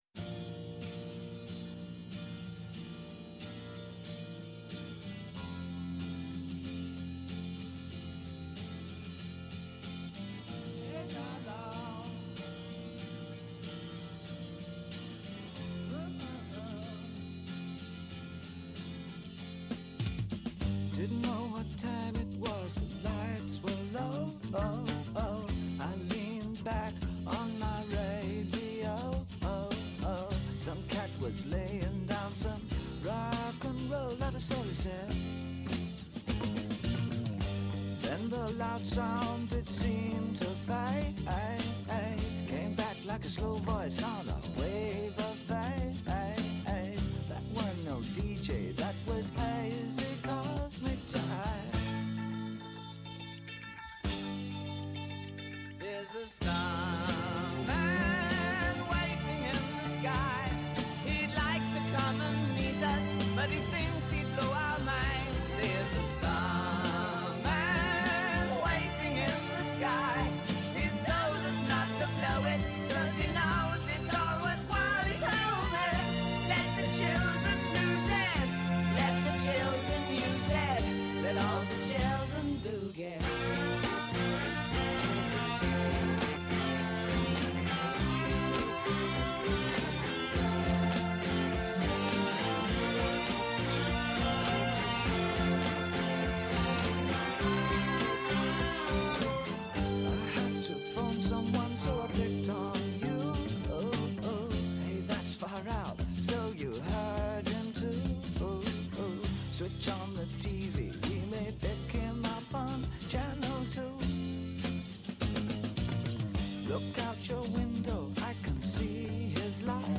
Kαλεσμένοι στην εκπομπή είναι κυβερνητικοί αξιωματούχοι, επιχειρηματίες, αναλυτές, τραπεζίτες, στελέχη διεθνών οργανισμών, πανεπιστημιακοί, φοροτεχνικοί και εκπρόσωποι συνδικαλιστικών και επαγγελματικών φορέων, οι οποίοι καταγράφουν το σφυγμό της αγοράς και της οικονομίας.